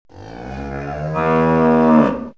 Cow 1 Bouton sonore
Animal Sounds Soundboard2,324 views